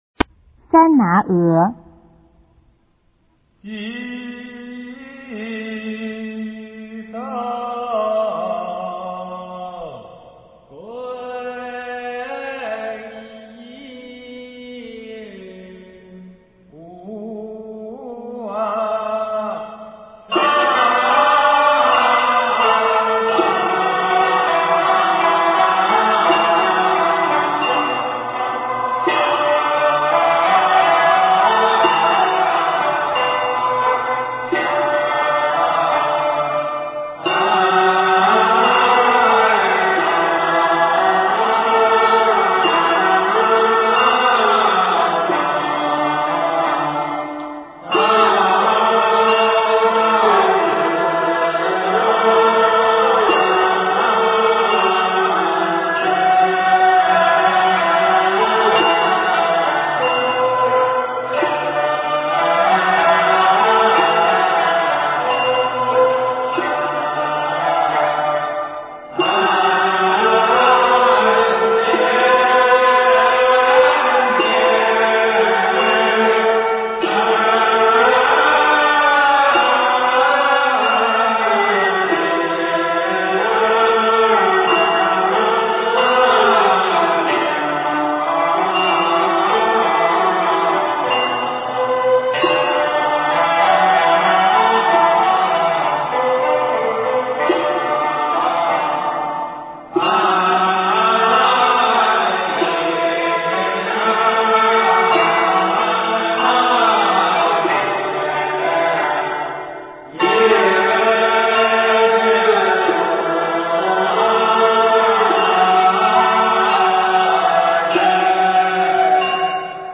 用途：三拿鹅 与「三信礼」配合共组的阴韵。「三信礼」是对三宝天尊的哀求启请，「三拿鹅」则是对三宝天尊的功德颂述。